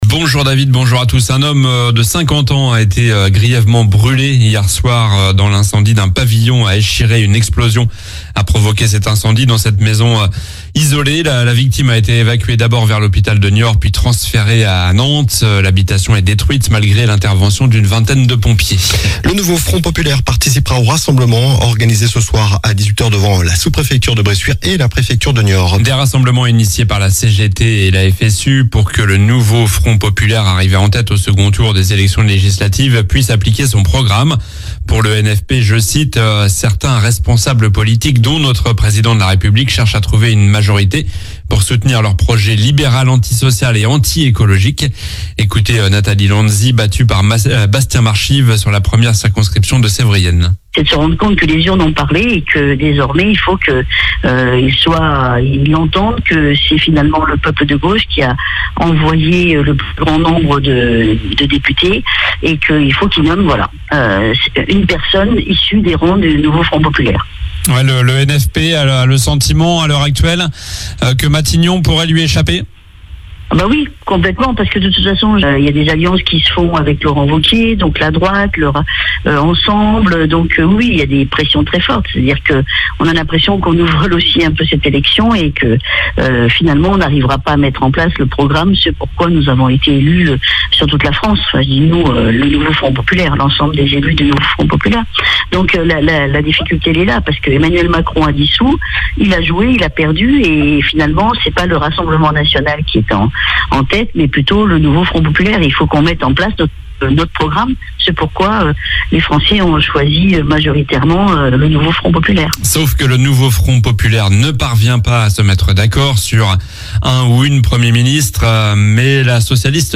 Journal du jeudi 18 juillet (matin)